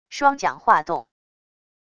双桨划动wav音频